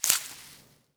harvest_5.wav